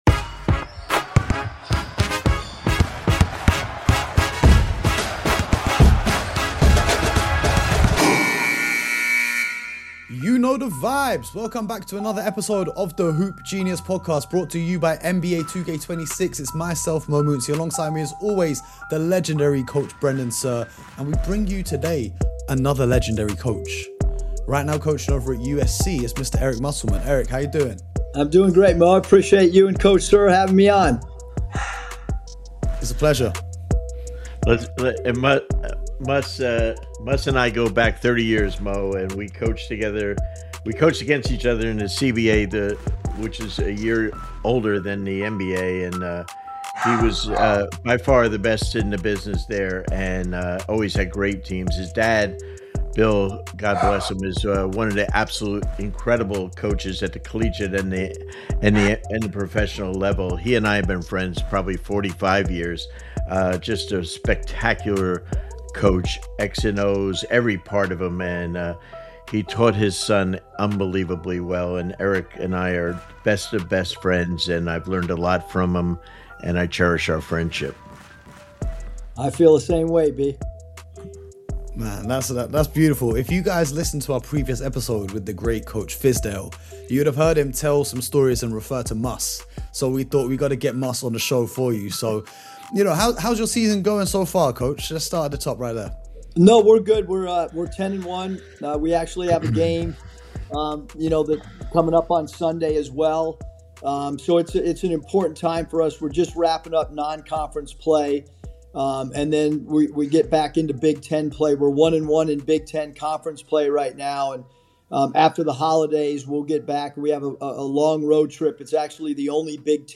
Eric Musselman joins the Hoop Genius Podcast for a no-filter conversation on the biggest lie in basketball: the idea that college hoops is “purer” than the NBA.